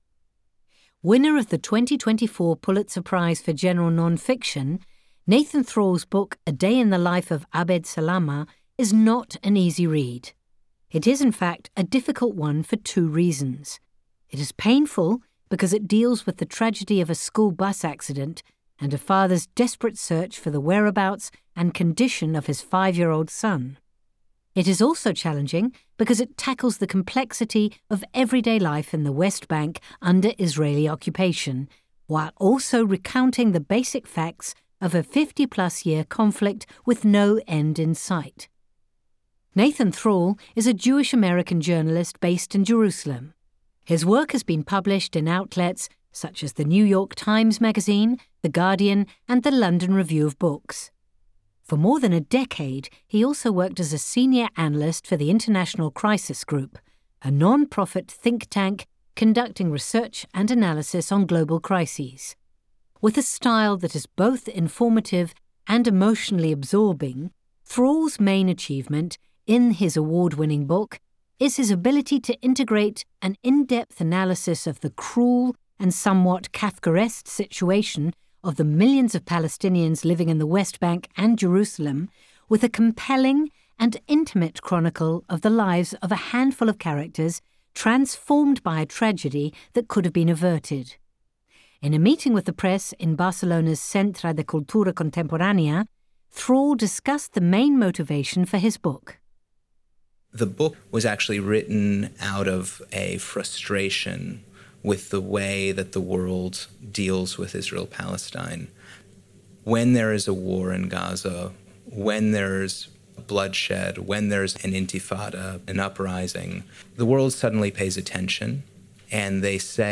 In a meeting with the press in Barcelona’s Centre de Cultura Contemporània, Thrall discussed the main motivation for his book. Nathan Thrall (American accent): The book was actually written out of a frustration with the way that the world deals with Israel-Palestine.